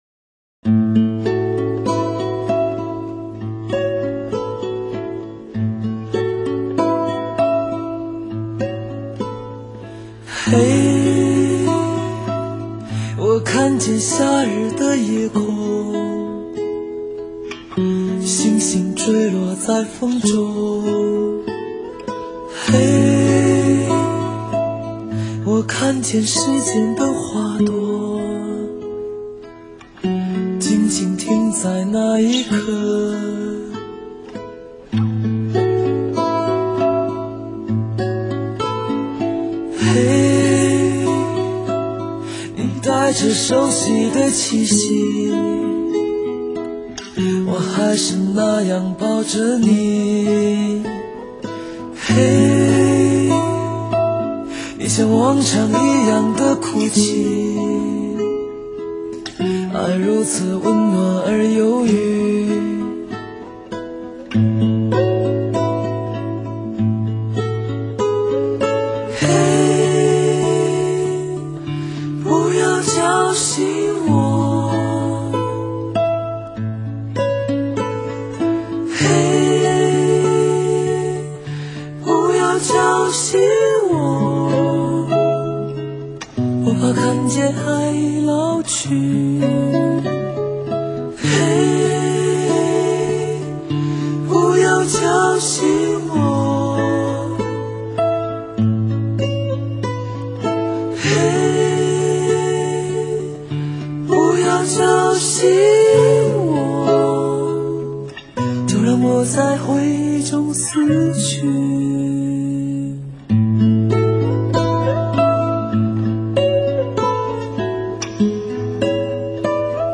恢弘的管弦乐、时尚的电子乐、张扬的硬摇滚、朴素的